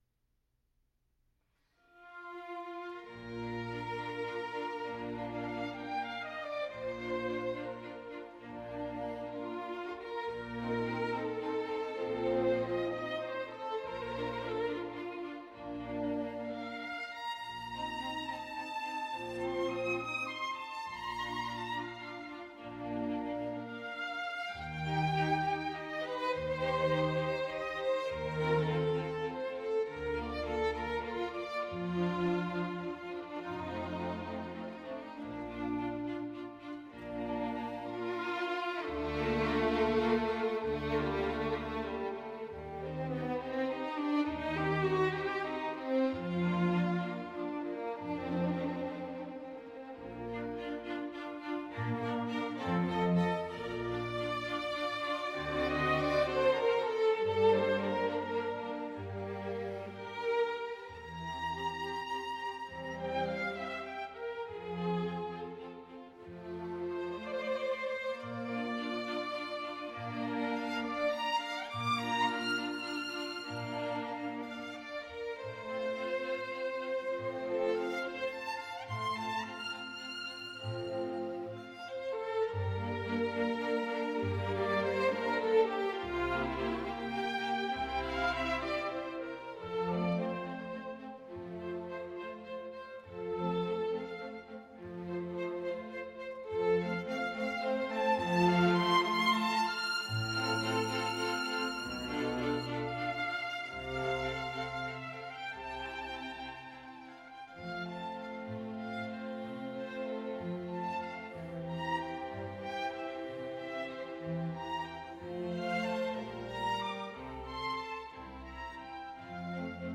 “Notturno in F Major”, P. 106 – III. Adagio by Michael Hayden, performed by Orpheus Chamber Orchestra: